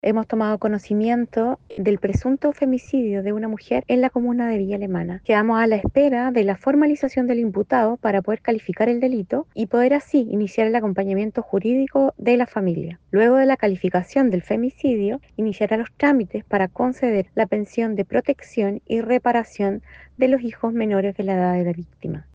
El delegado presidencial regional, Yanino Riquelme, aportó antecedentes señalando que la persona que alertó del siniestro es el propietario de los terrenos y que la mujer fallecida es su hija, además de señalar que el consumo de alcohol podría estar relacionado con el tráfico hecho.